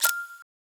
Click (8).wav